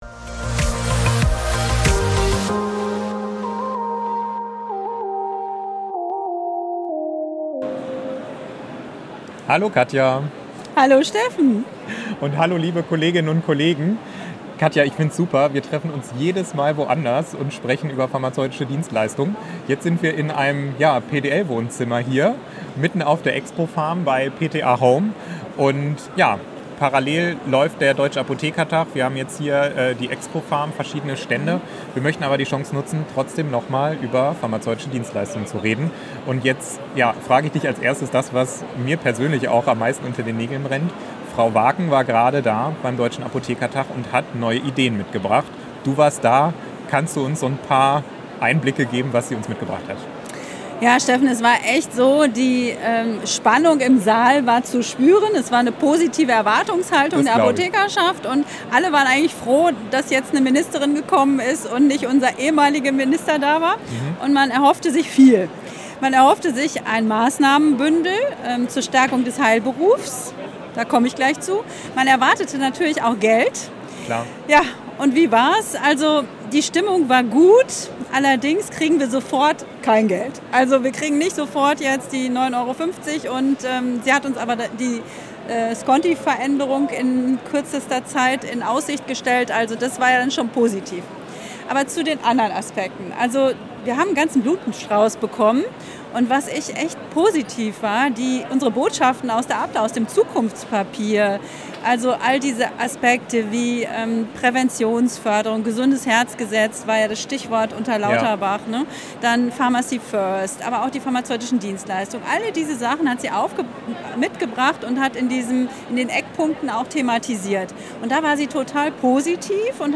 Live von der expopharm: Hot Topics des DAT & die Rolle der PTA bei den pDL